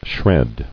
[shred]